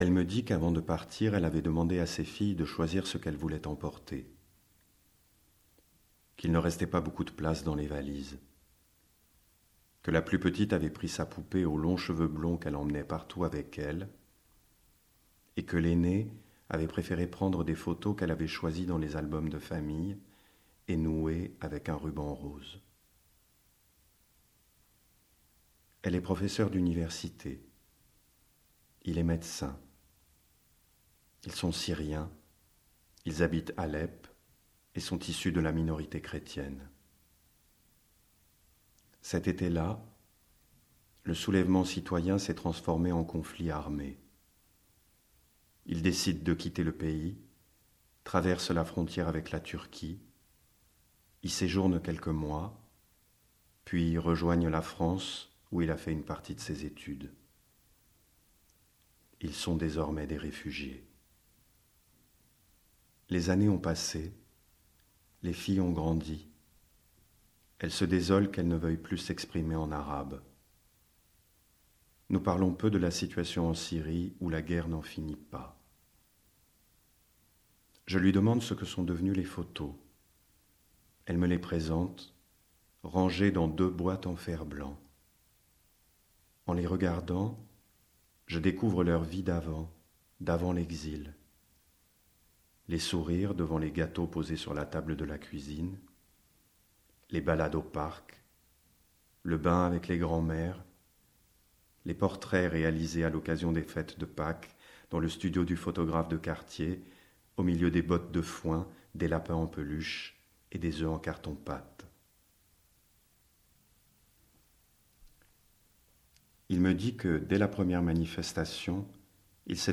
Voix : Éric Génovèse de la Comédie-Française